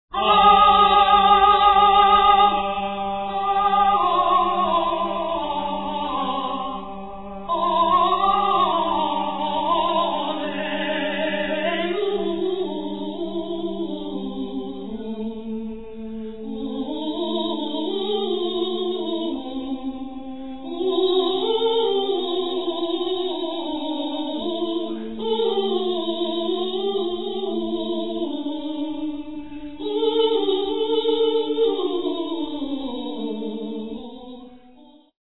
countertenor
tenors
viol